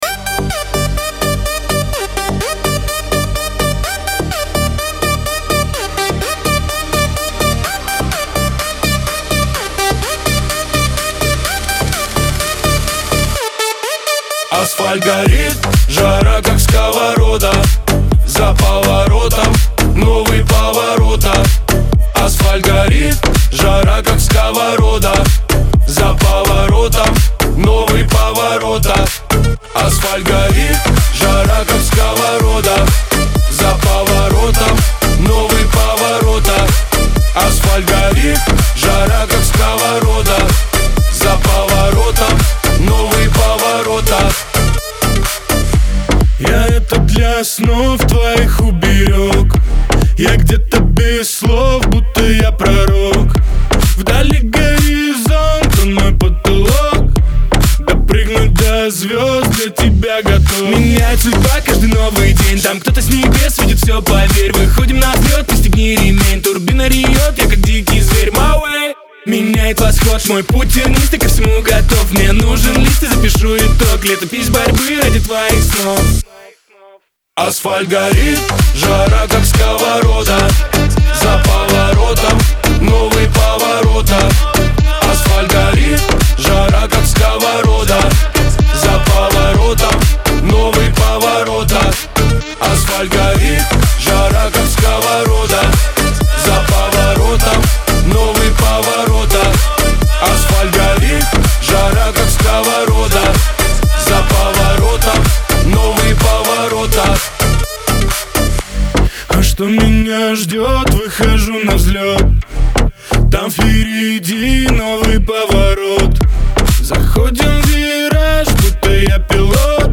дуэт
диско , эстрада , pop
Шансон